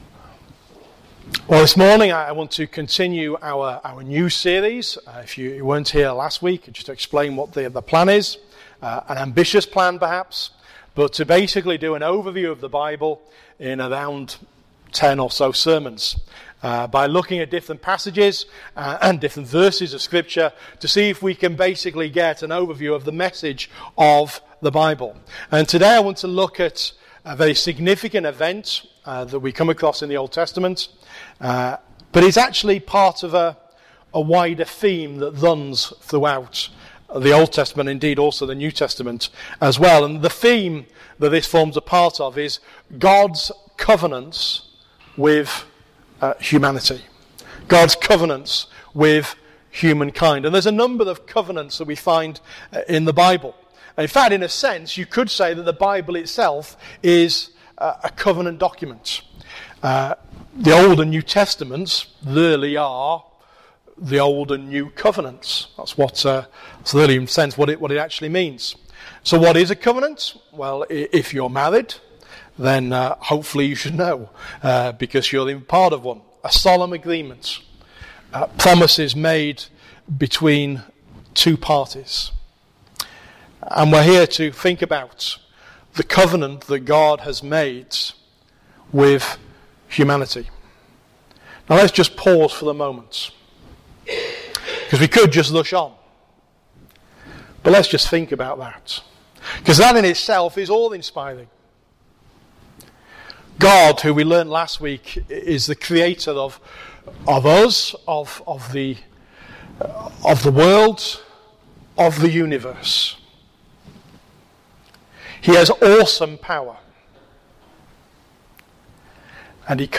Sermon Recording and Outline on Genesis Chapter 12 verses 1-9